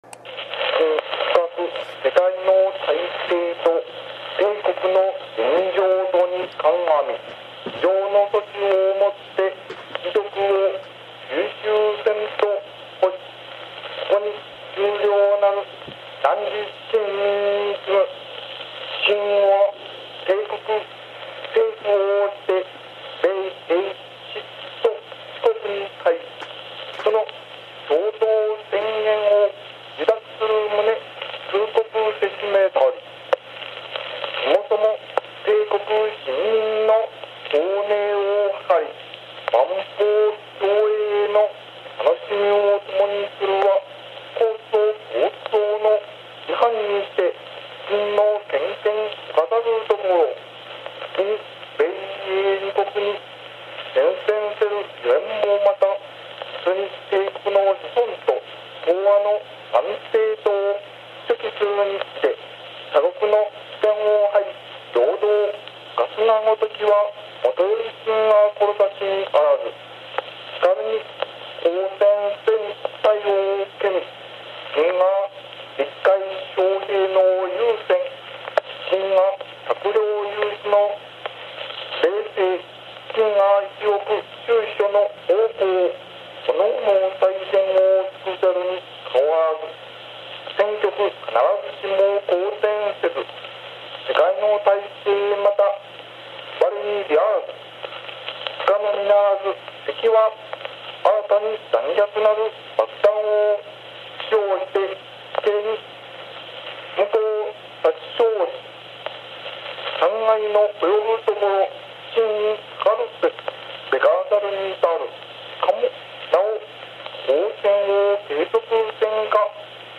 太平洋戦争（大東亜戦争）関連のラジオ放送録音資料
終戦の玉音放送
玉音放送 （ ４分４０秒 ）　　　の　試聴mp3　←クリック